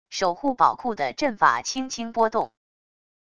守护宝库的阵法轻轻波动wav音频